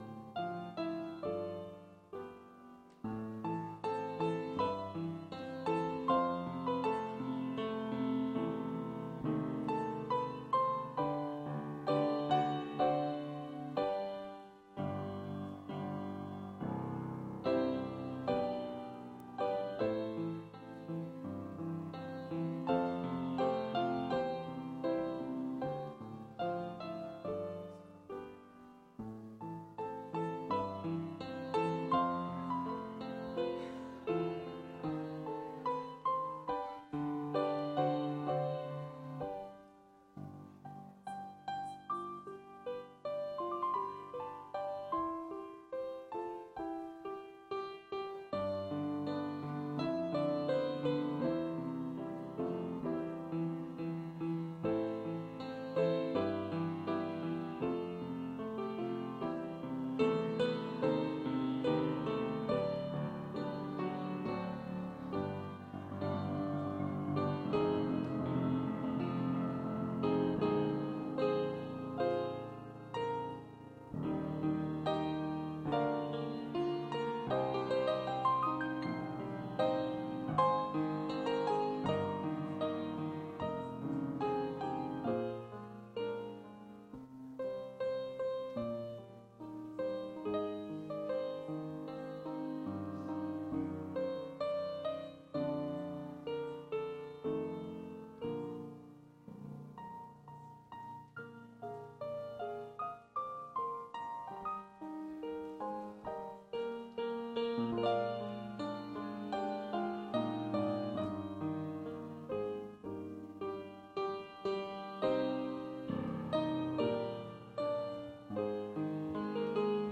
Calvin Christian Reformed Church Sermons
ORDER OF WORSHIP